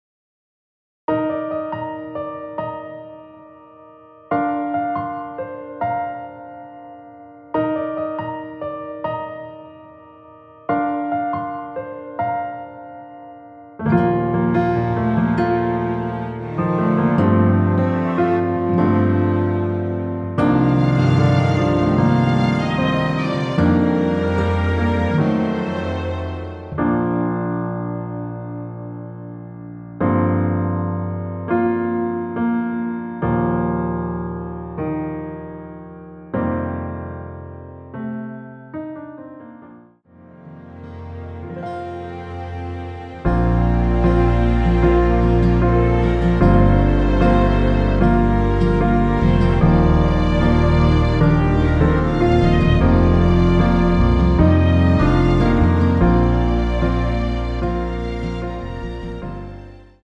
원키에서(-3)내린 MR입니다.
Bb
앞부분30초, 뒷부분30초씩 편집해서 올려 드리고 있습니다.
중간에 음이 끈어지고 다시 나오는 이유는